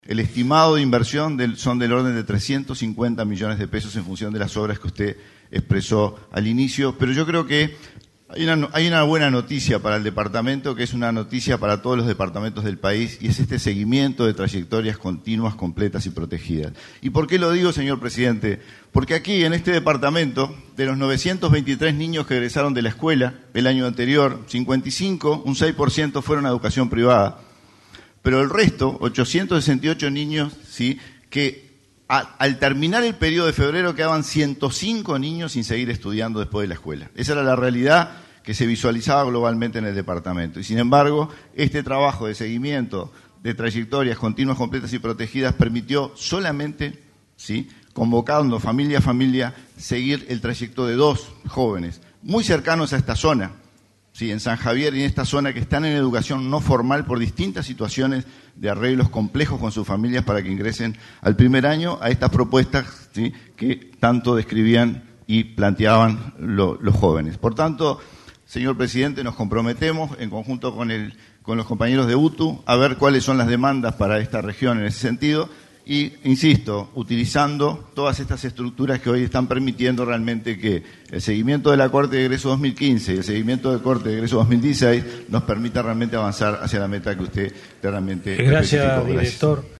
Río Negro recibirá 350 millones de pesos de inversión para centros educativos, anunció el presidente del Codicen, Wilson Netto, durante el Consejo de Ministros Abierto de Nuevo Berlín. Anunció que de 923 egresados de Primaria solo dos niños no están incorporados al sistema y que se están analizando alternativas para su reinserción.